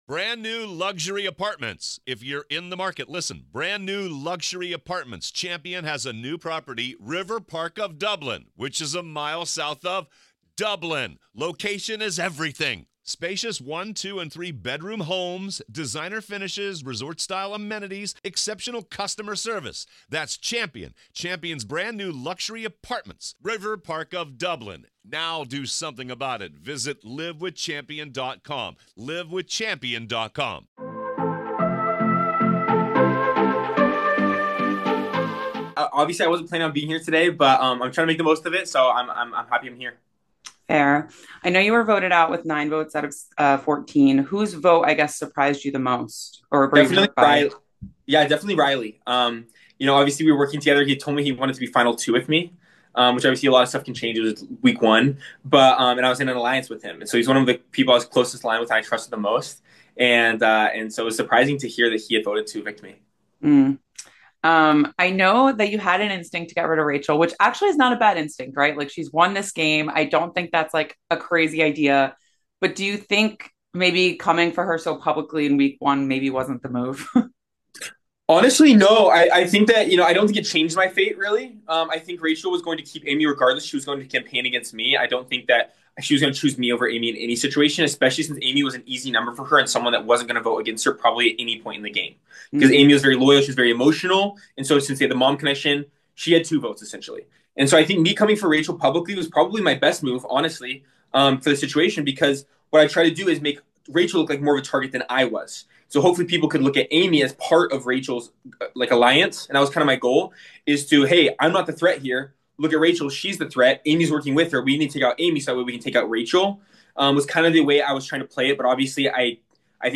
Big Brother 27 Exit Interview